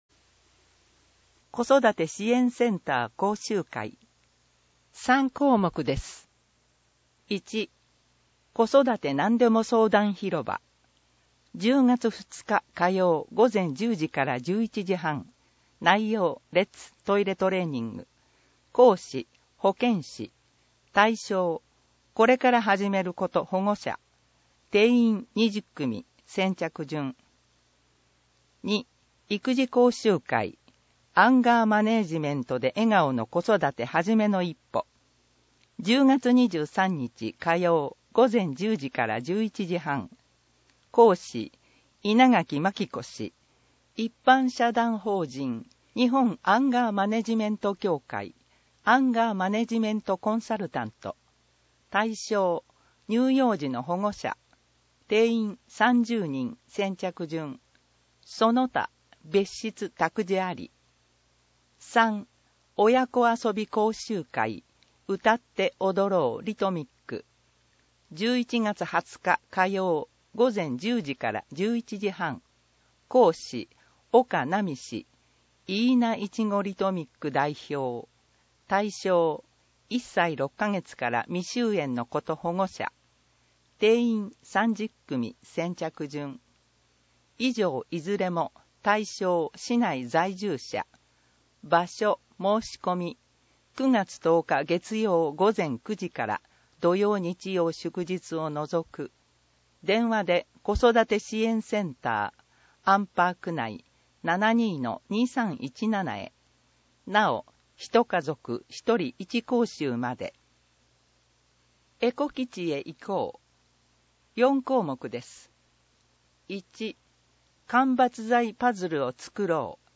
広報あんじょうの音声版を公開しています
なお、以上の音声データは、「音訳ボランティア安城ひびきの会」の協力で作成しています。